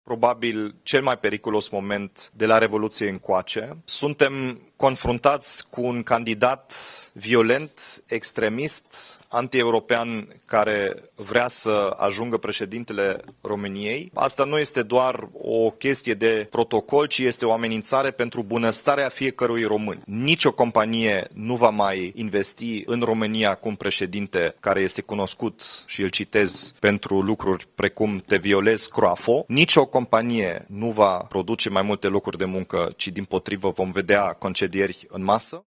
Companiile vor părăsi România și vom pierde locurile de muncă dacă Simion va fi ales, a mai declarat Dominic Fritz la o conferință de presă: